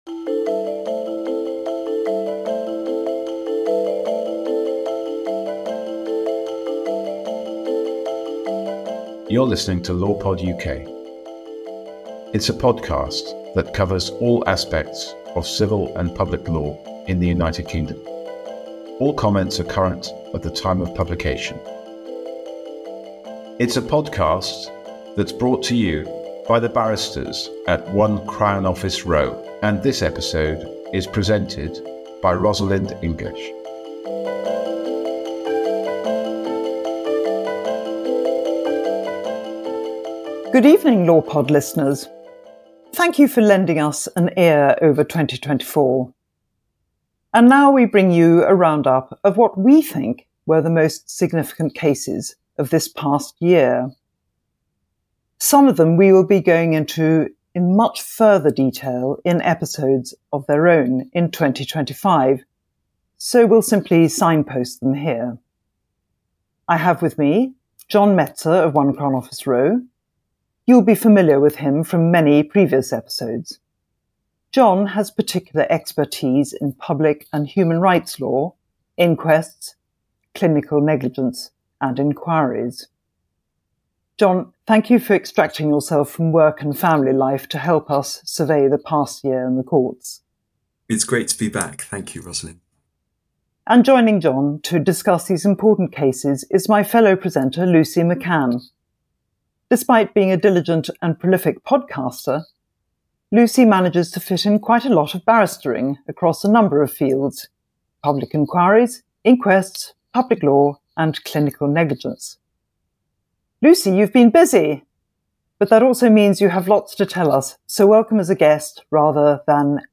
Join the Law Pod team as we discuss a range of cases decided at all levels in the courts in 2024 with important implications for the future.